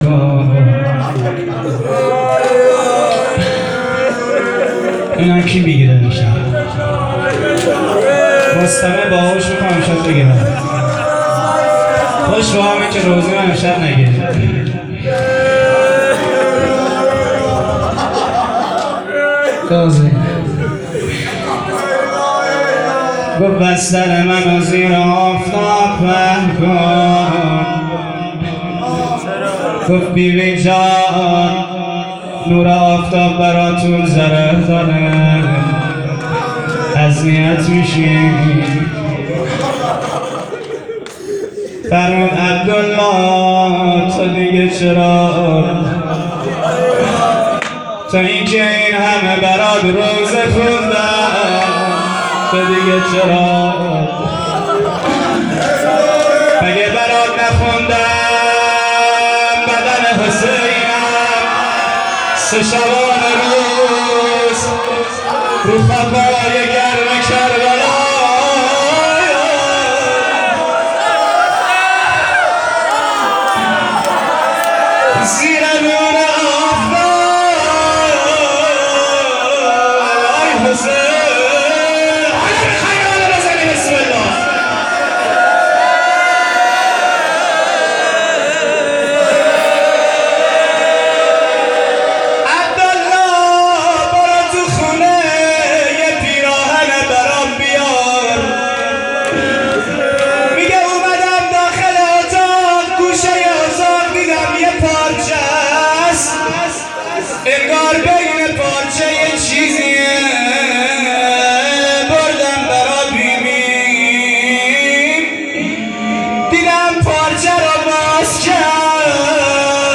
روضه - مدافعان حرم 3.m4a
روضه-مدافعان-حرم-3.m4a